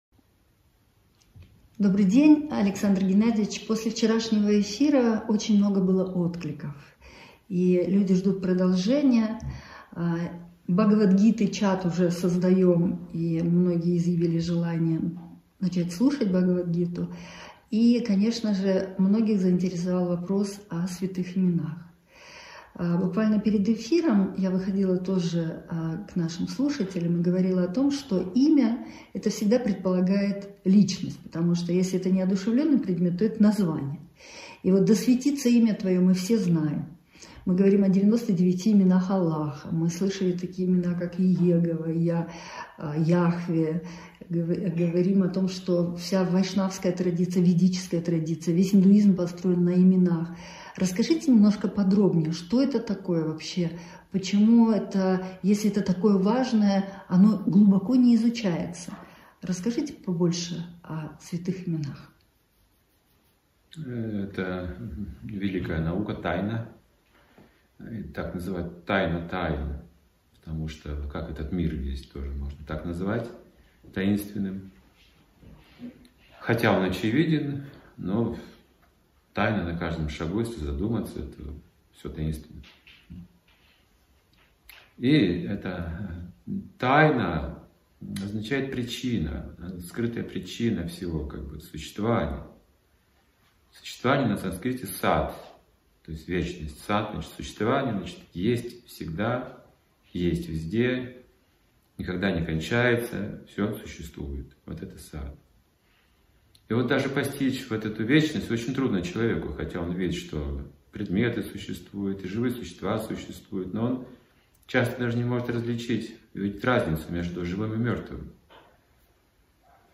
Алматы
Беседа Учителя и ученика